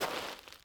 mining sounds
SAND.3.wav